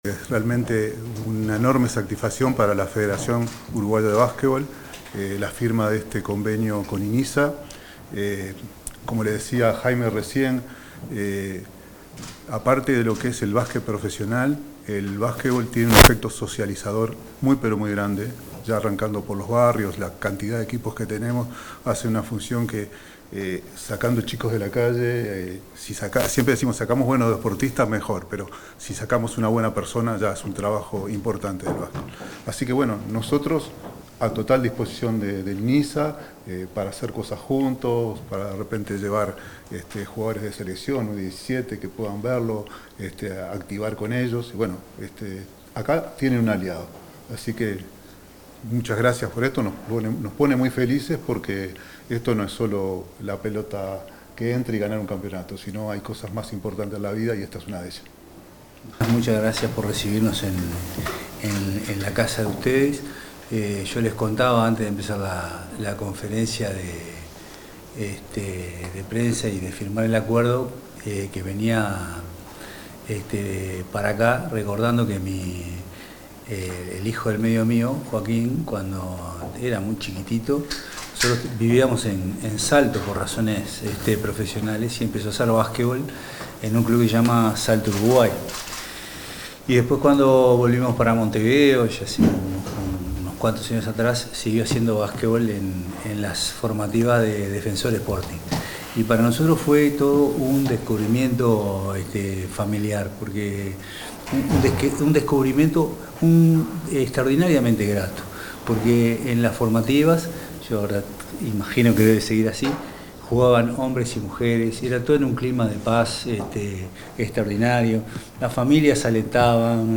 Palabras del presidente del Inisa, Jaime Saavedra